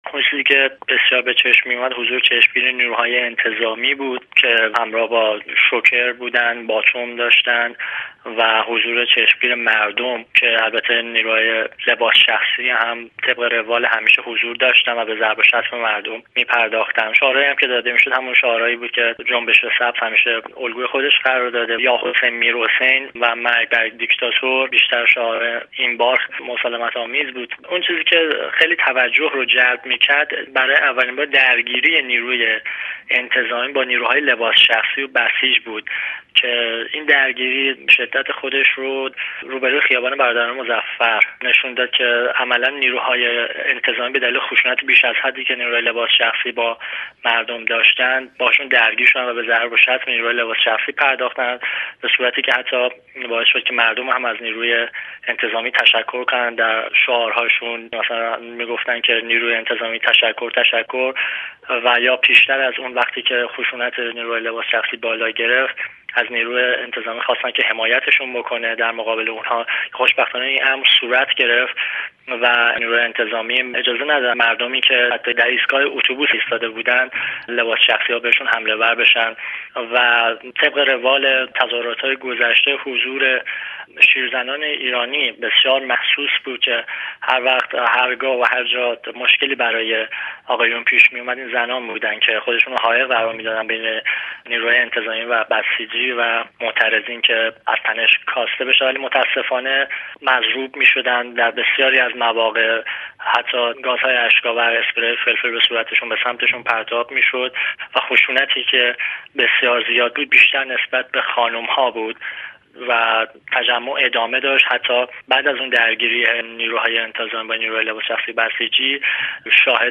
گزارشی از یک شاهد عینی - تظاهرات تهران
Manifestations_Etudients_Son.mp3